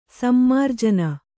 ♪ sammarjana